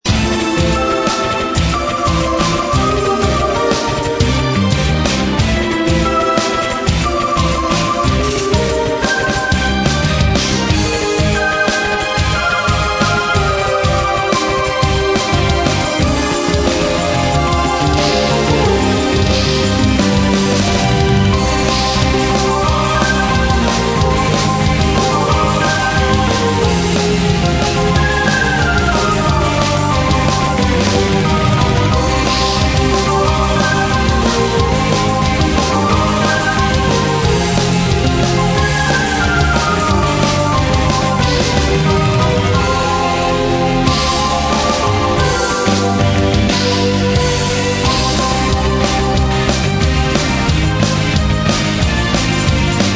作編曲・演奏(Drums,Piano,Keyboards
[Bass,Guitar,etc])：